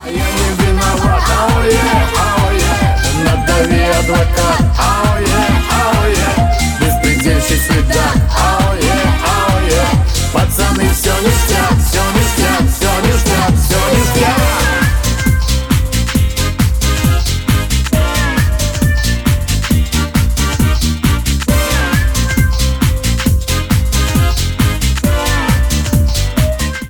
• Качество: 128, Stereo
позитивные
громкие
арестантские